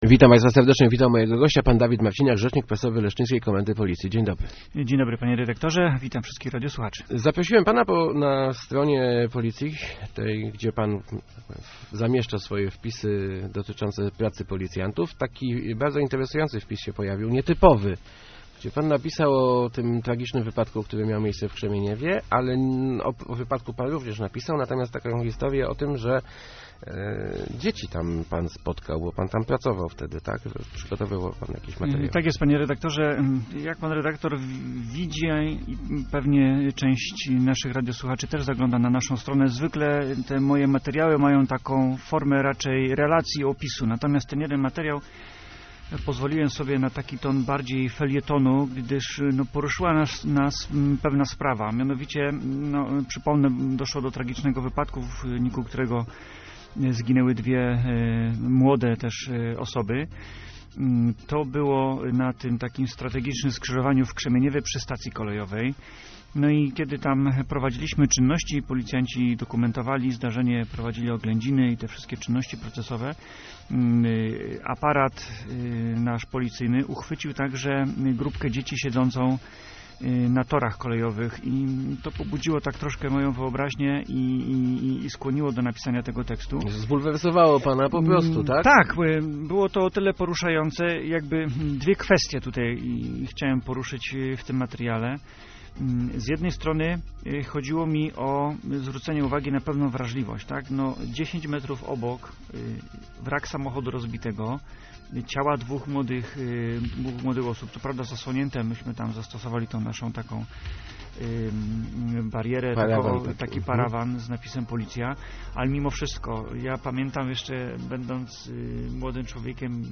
Start arrow Rozmowy Elki arrow Dzieci patrzą na tragedię